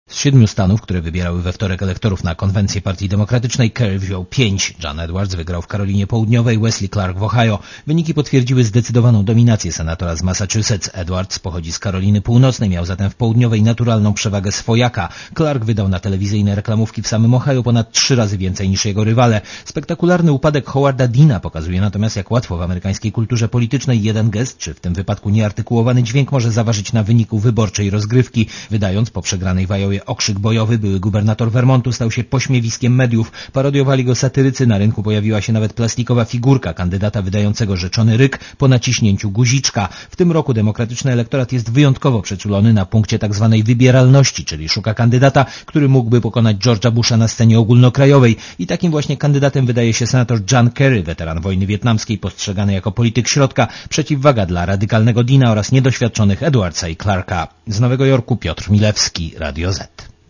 Korespondencja z USA (236Kb)